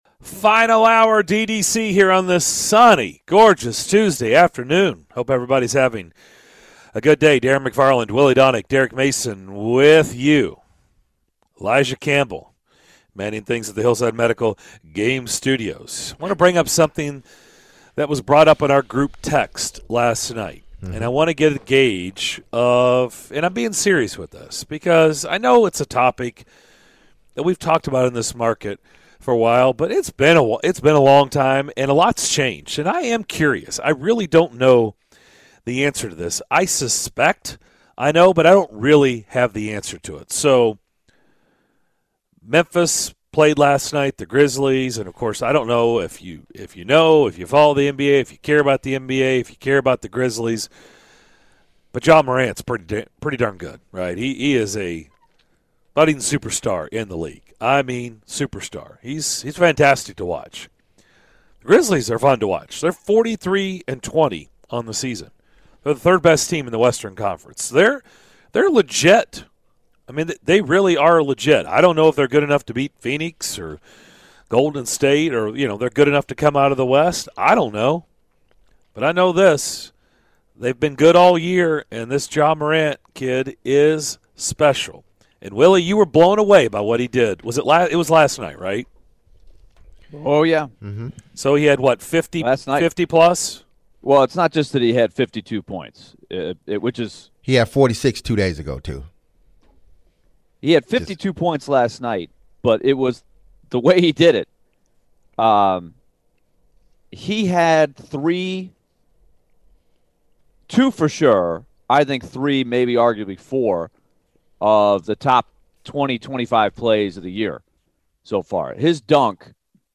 We take your calls and texts over the Grizzlies and close out the show